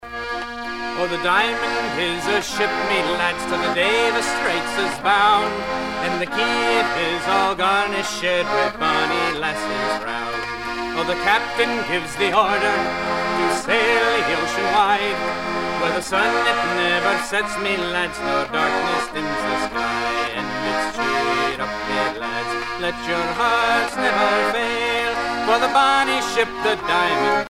Sea chanteys and sailor songs
Pièce musicale éditée